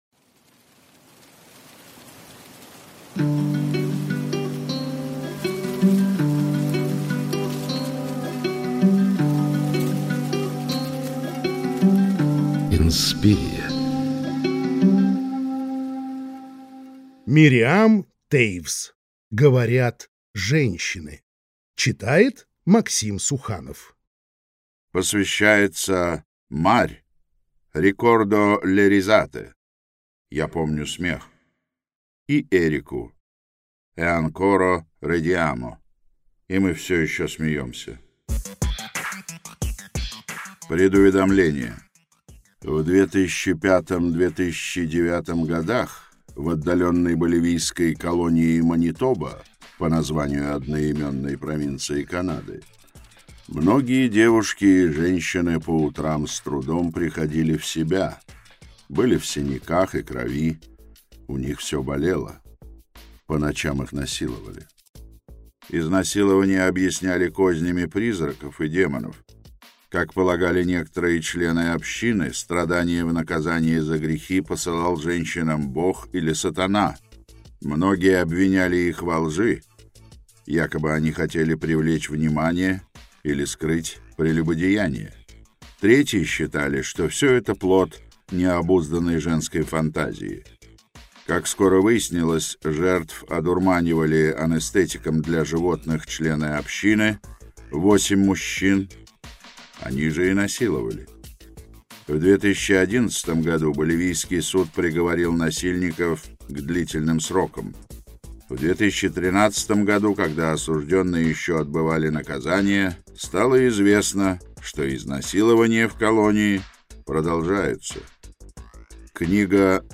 Аудиокнига Говорят женщины | Библиотека аудиокниг